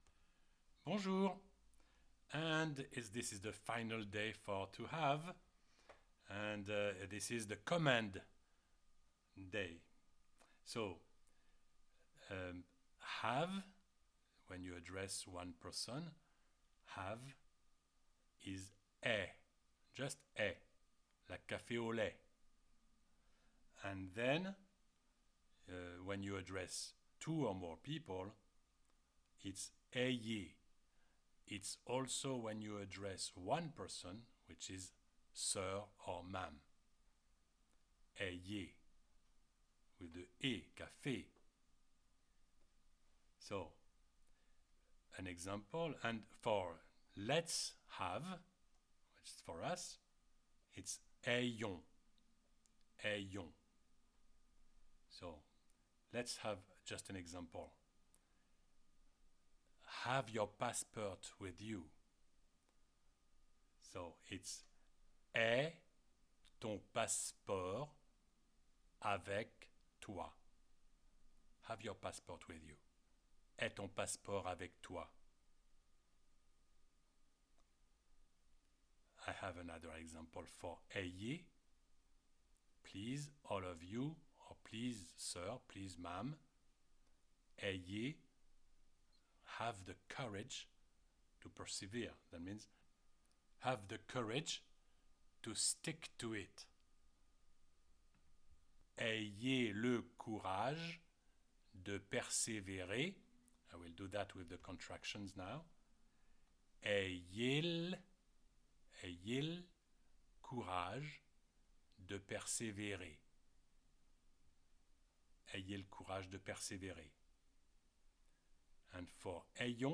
french-conjugation-with-pronunciation.mp3